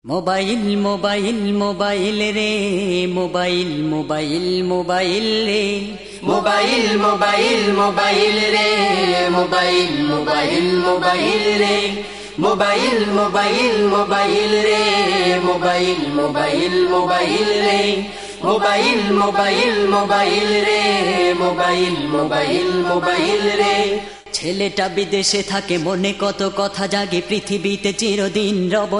Category: Islamic Ringtones